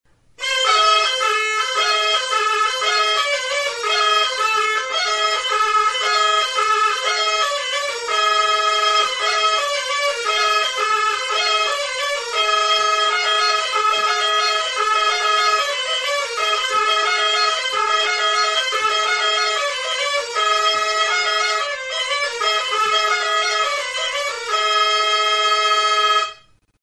Aérophones -> Anches -> Simple battante (clarinette)
Enregistré avec cet instrument de musique.
Mihi bakunadun klarinete bikoitza da.